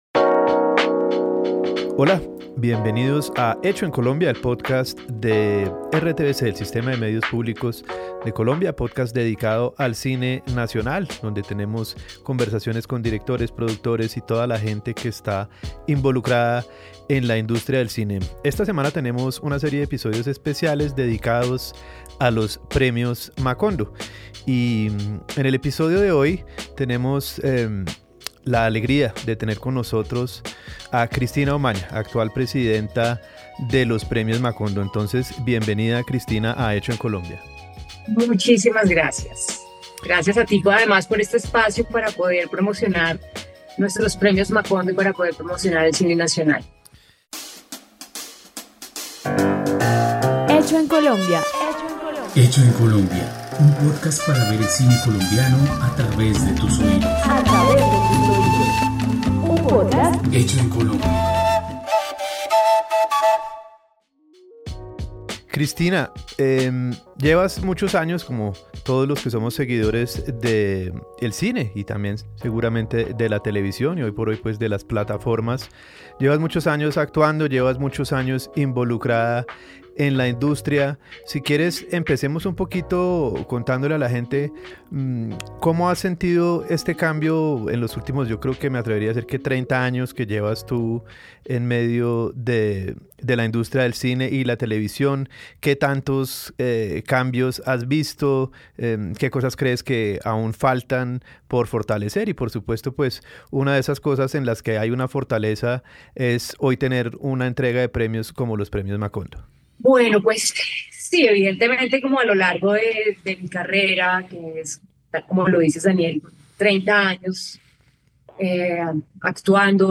..Pódcast. Escucha ahora la entrevista a Cristina Umaña, directora de la Academia Colombiana de Cine y los Premios Macondo, en RTVCPlay.